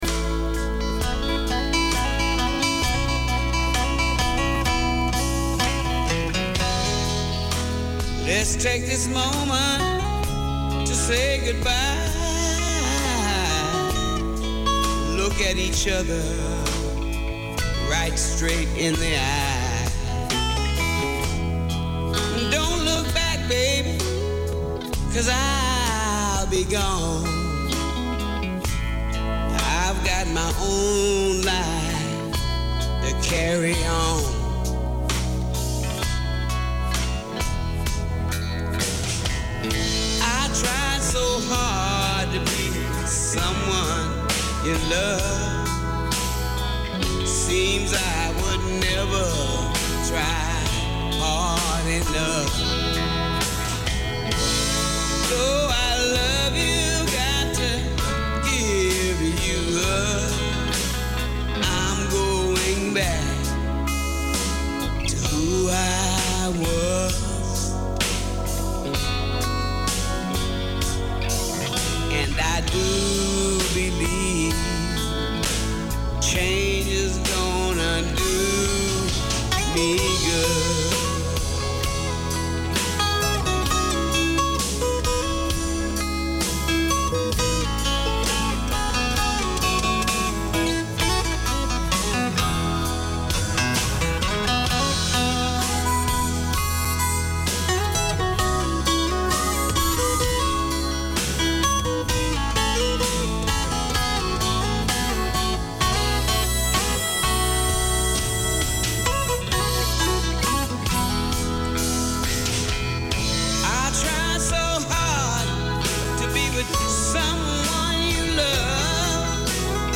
Community Access Radio in your language - available for download five minutes after broadcast.
A programme for Sri Lankan Kiwis in Auckland and beyond, Sriwi Hada offers practical advice on everything from legal issues, immigration, getting around the city, and everything you need to know for surviving and thriving in Aotearoa. All this, and great music!